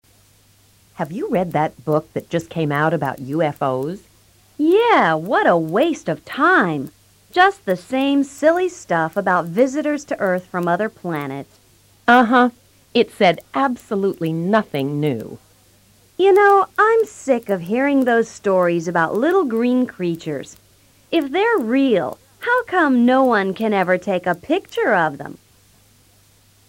Cuatro personas opinan sobre libros y cine.